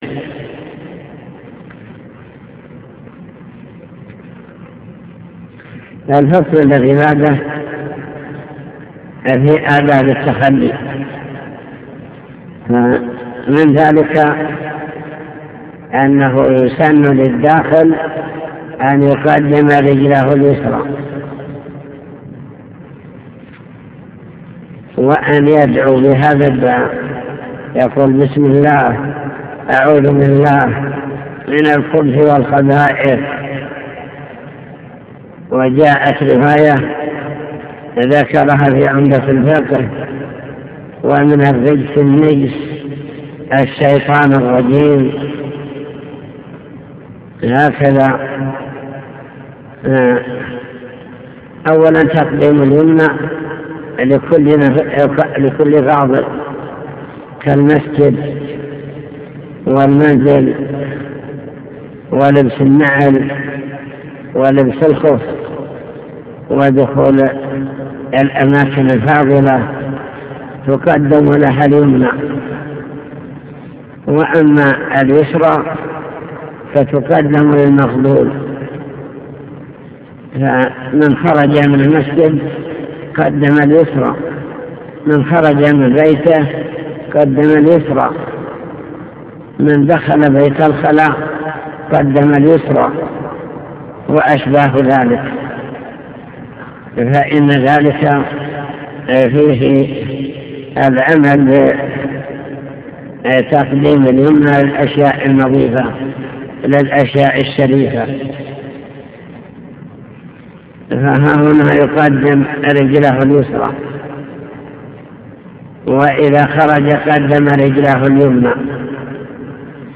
المكتبة الصوتية  تسجيلات - كتب  شرح كتاب دليل الطالب لنيل المطالب كتاب الطهارة باب الاستنجاء آداب التخلي